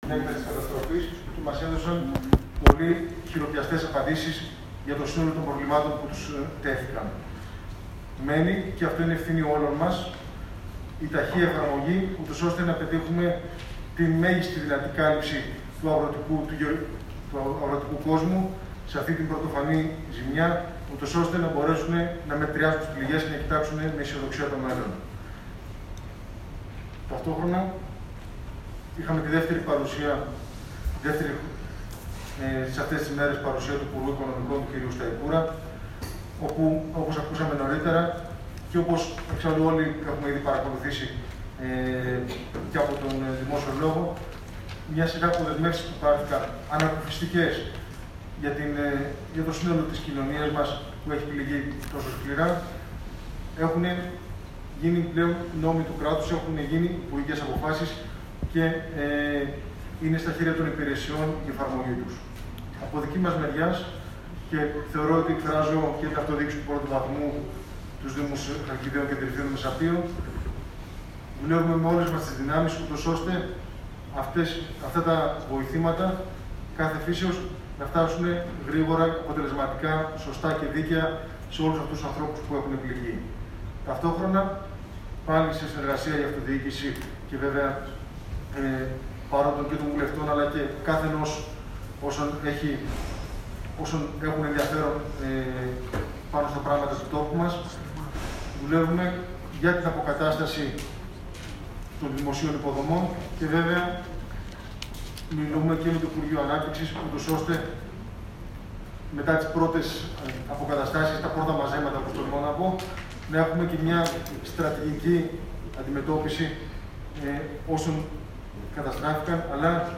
Αποκλειστικά οι δηλώσεις Βορίδη,Σταϊκούρα, και Σπανού μετά τη σύσκεψη στο διοικητήριο της Χαλκίδας [ηχητικό]
Ακούστε αποκλειστικά τι δήλωσαν ο Μάκης Βορίδης, ο Χρήστος Σταικούρας και ο Φάνης Σπανός.